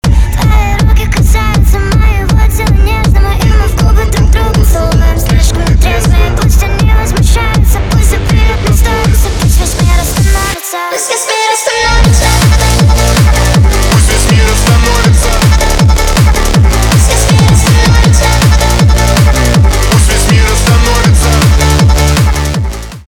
русский рэп , битовые , басы , качающие